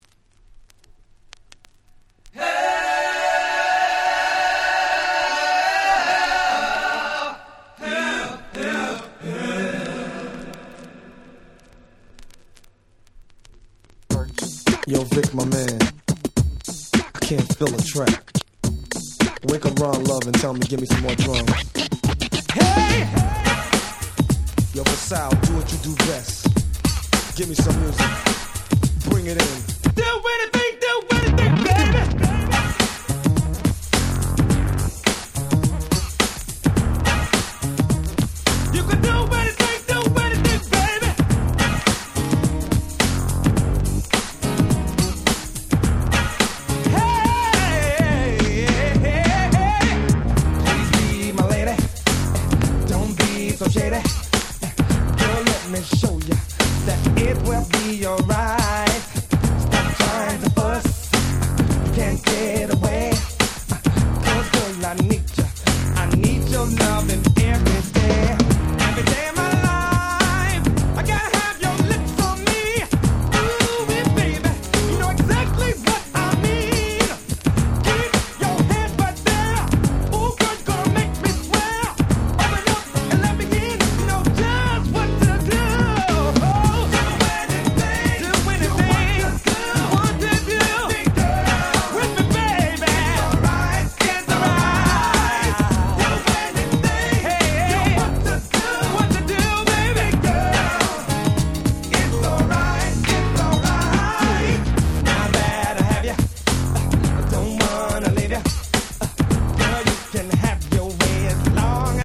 93' Nice R&B/New Jack Swing LP !!
A面はハネ系New Jack Swing中心、B面は激甘Slow中心。